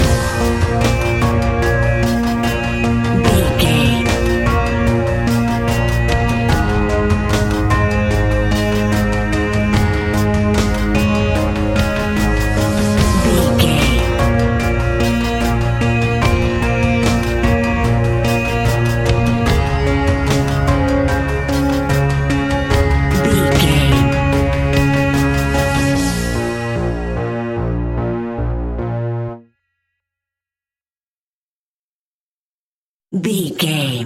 Aeolian/Minor
B♭
ominous
dark
suspense
eerie
synthesiser
drums
ticking
electronic music